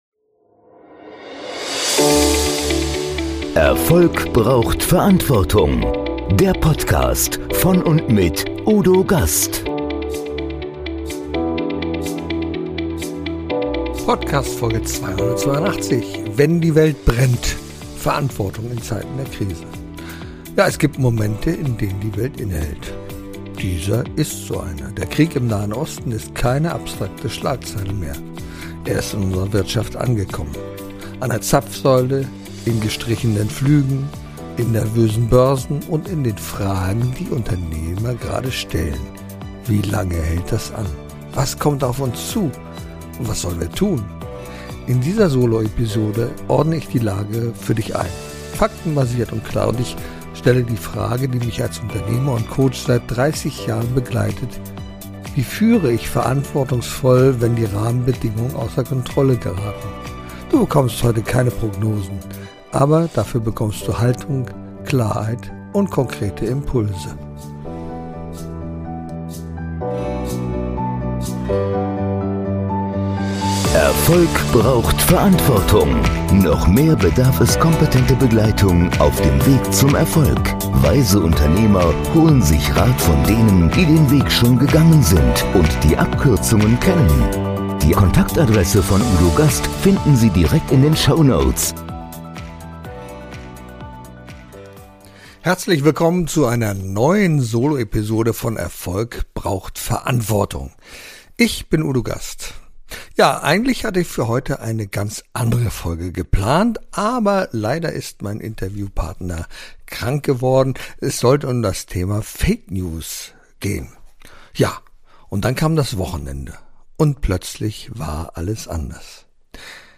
In dieser Solo-Episode ordne ich die Lage für dich ein — faktenbasiert und klar.